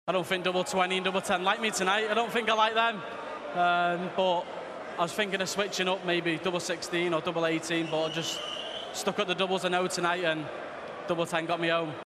Littler tells Sky Sports News, he's just pleased to get through.